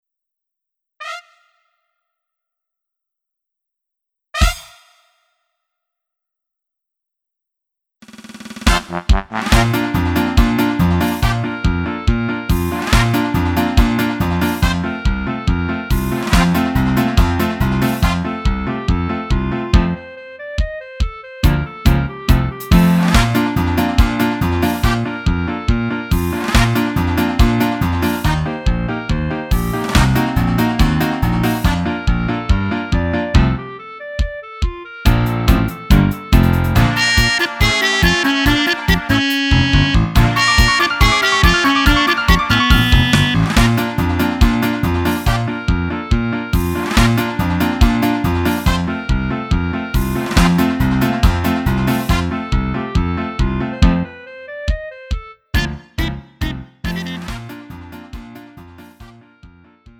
음정 -1키 3:56
장르 구분 Lite MR